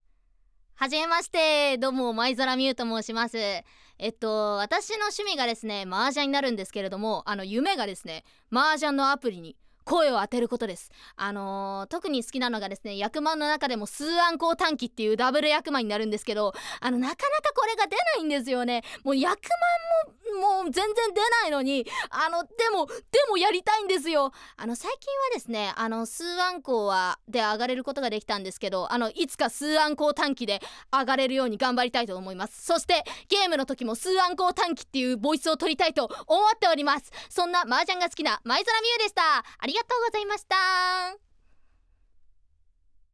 ボイスサンプル
フリートーク